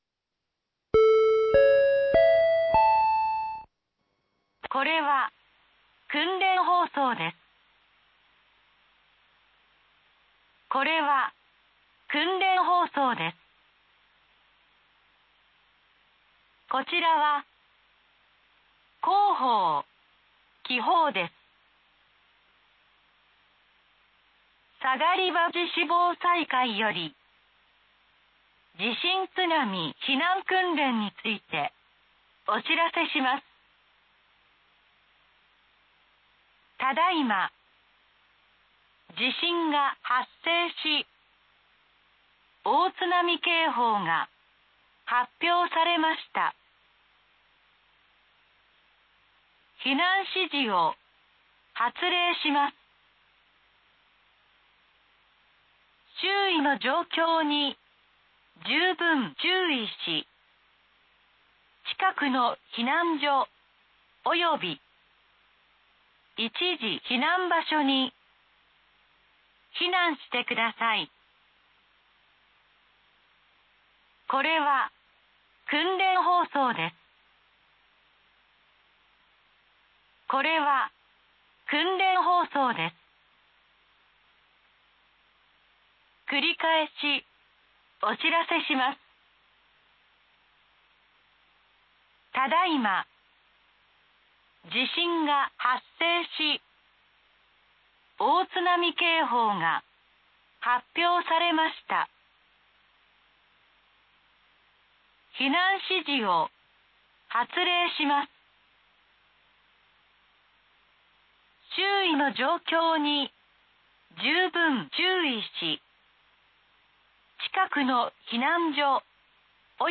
【訓練放送】地震津波避難訓練について